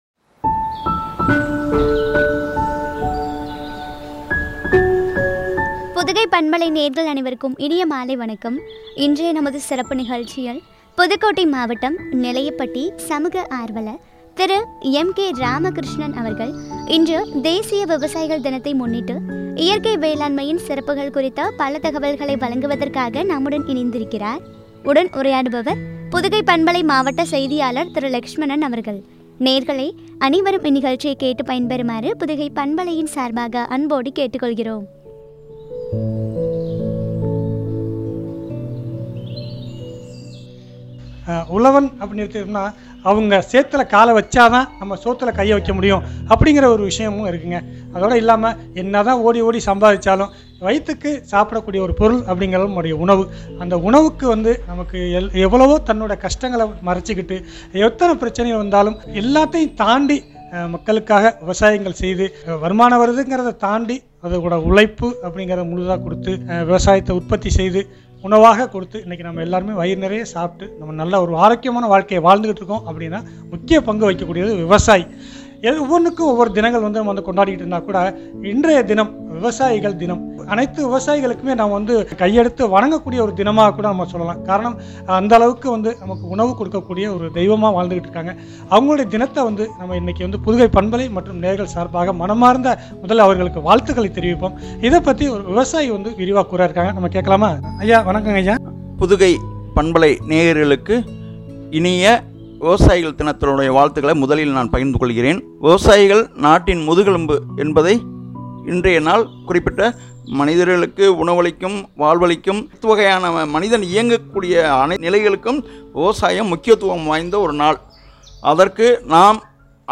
இயற்கை வேளாண்மையின் சிறப்புகள் பற்றிய உரையாடல்.